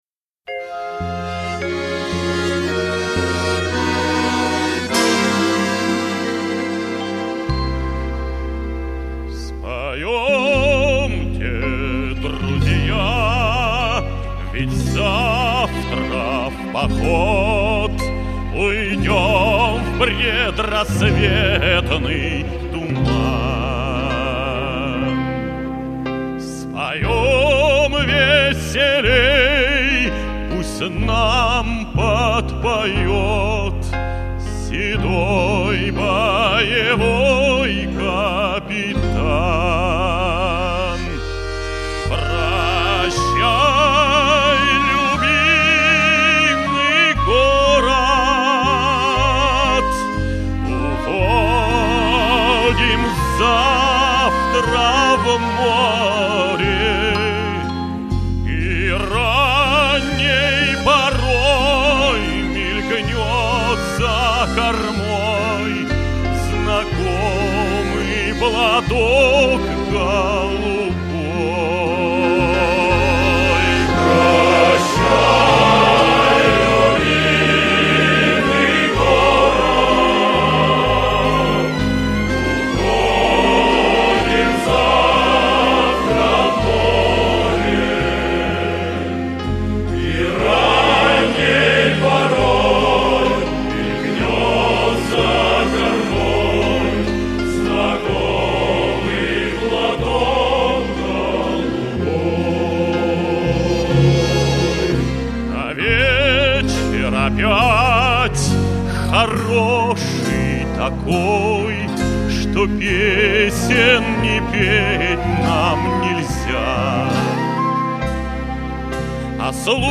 Исполняется на гастролях во Франции в 2010 году.